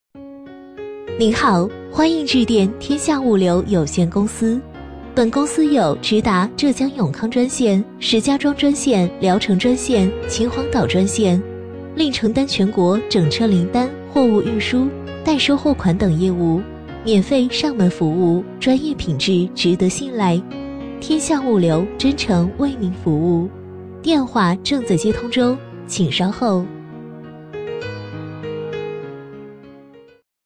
A类女50
【女50号彩铃】天下物流有限公司
【女50号彩铃】天下物流有限公司.mp3